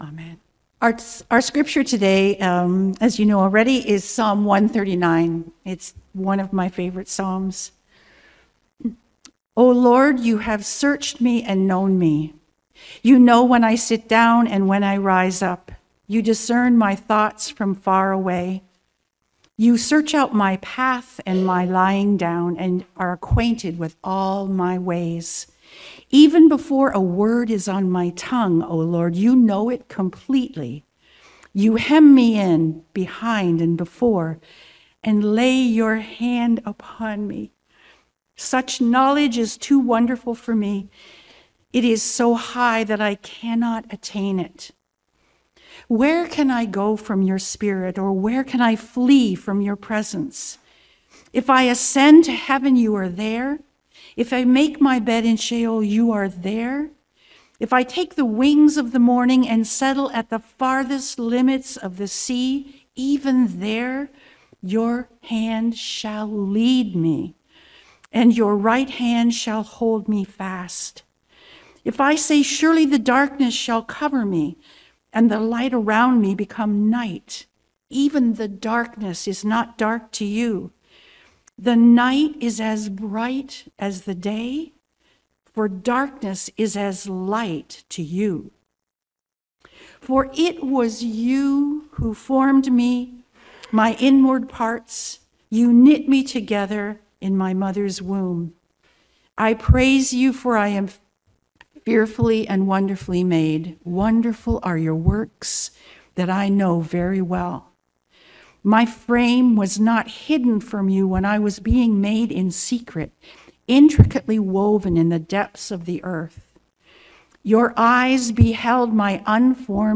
Subscribe via iTunes to our weekly Sermons